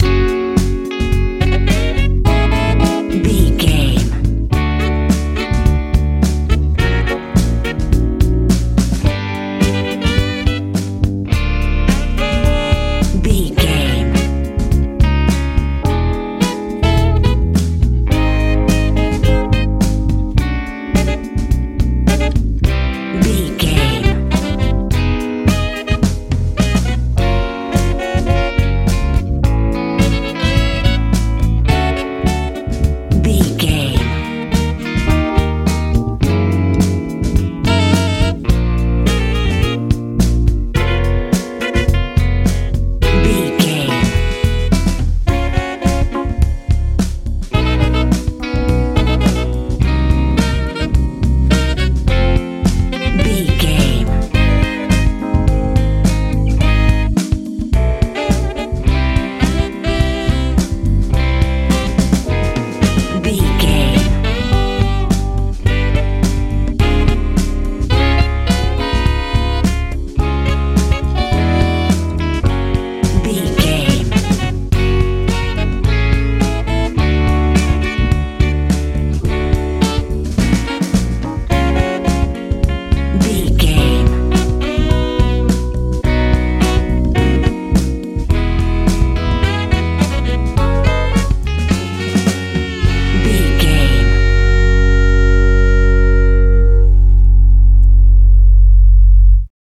funky feel
Ionian/Major
C♯
groovy
horns
electric guitar
piano
bass guitar
drums
joyful
lively